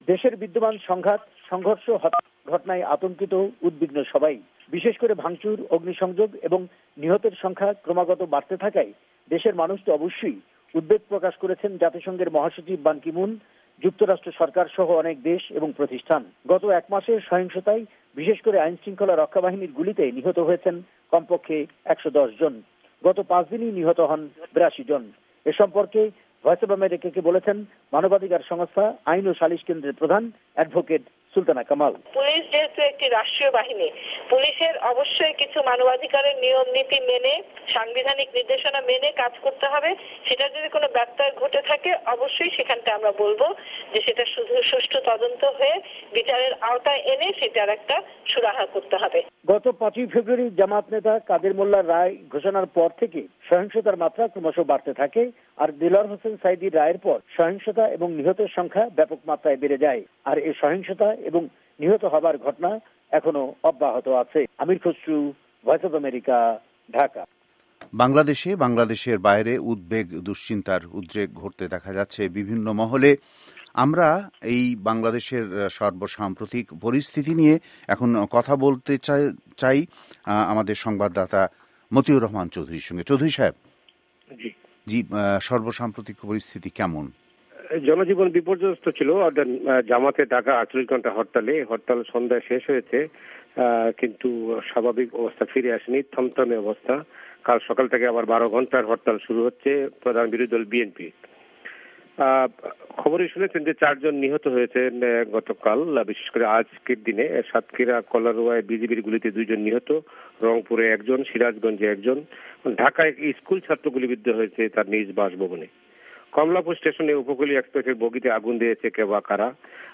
আন্তর্জাতিক টেলিকনফারেন্স লাইনে